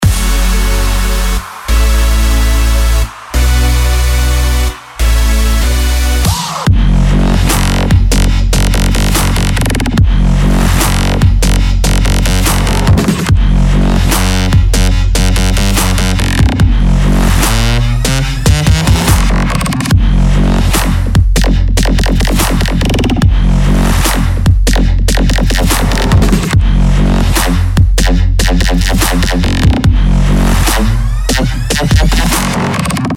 • Качество: 192, Stereo
Новое Трэп-Рубилово на твой телефон!